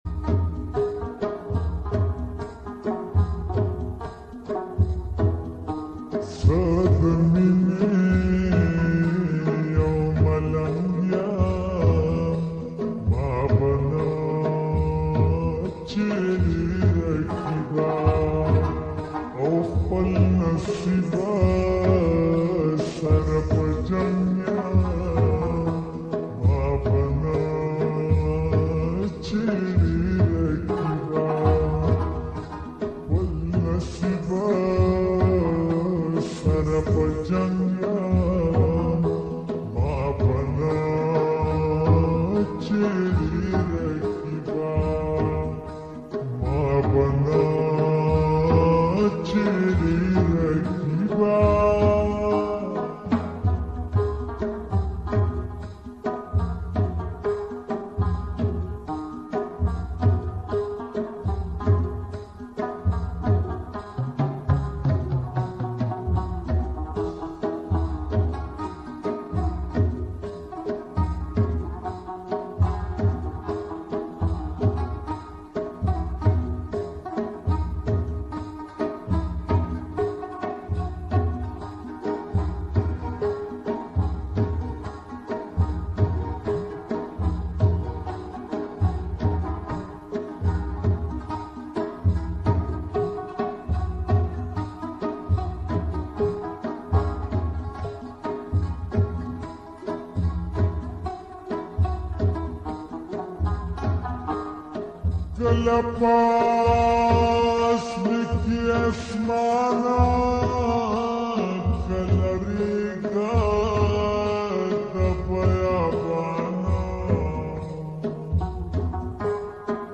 𝙨𝙡𝙤𝙬𝙚𝙙+𝙧𝙚𝙫𝙚𝙧𝙗 𝙨𝙖𝙙 𝙨𝙤𝙣𝙜